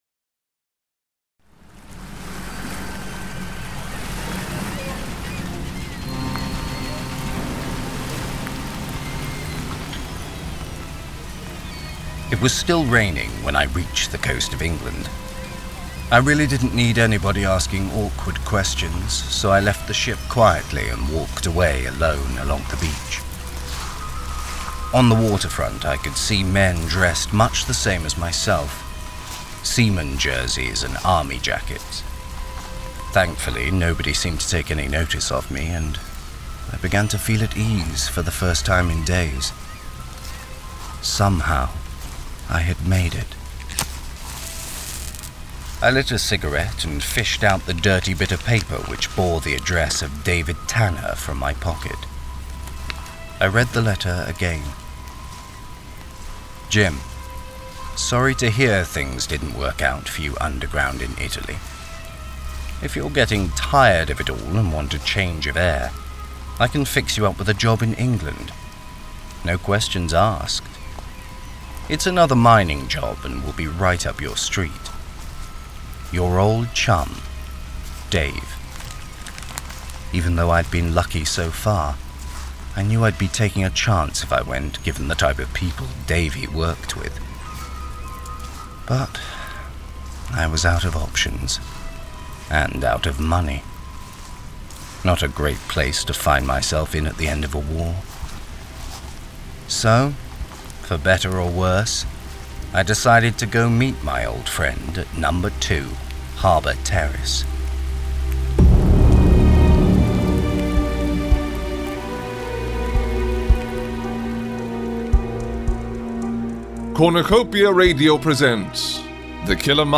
Our remake of the 'Escape' episode by Hammond Innes.
It has been remade for this years ‘Sonic Society’ summerstock festival in which modern audio-fiction groups take on the challenge of remaking classic ‘Old Time Radio’ shows.